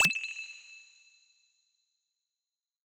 generic-hover.wav